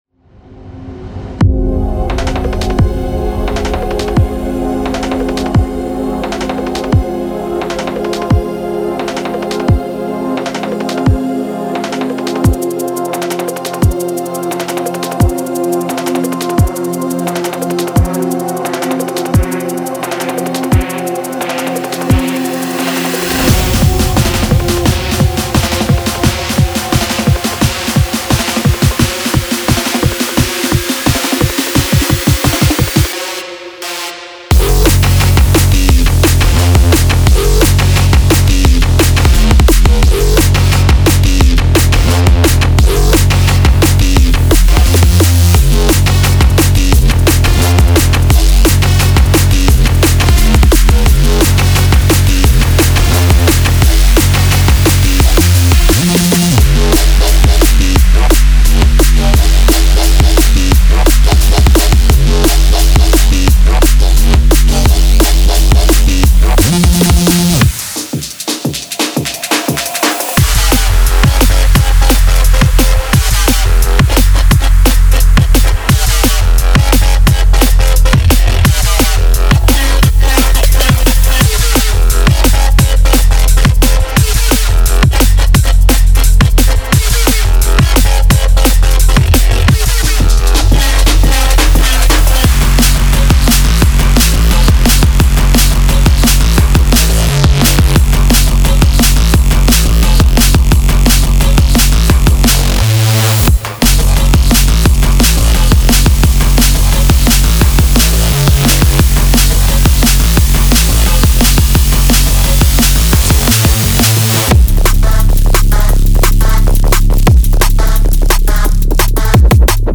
Genre:Neurofunk
デモサウンドはコチラ↓